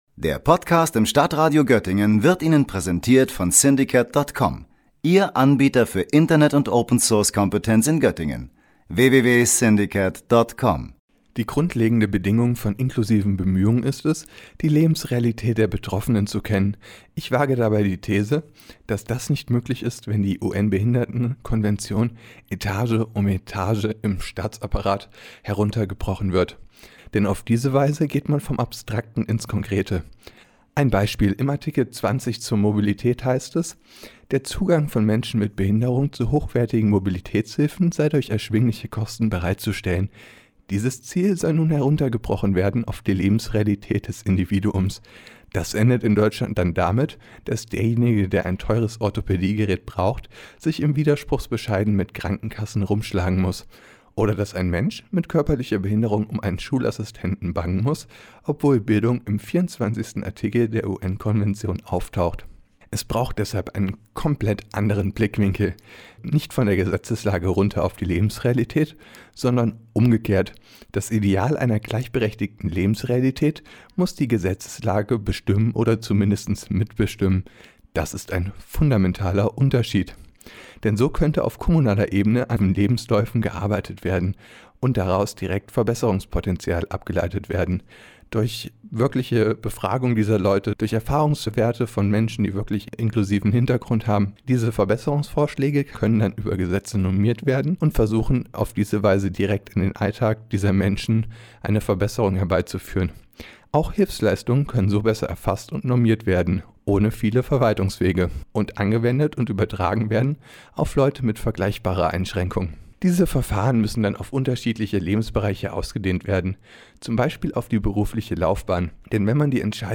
Beiträge > Kommentar: Inklusiver Landkreis Göttingen?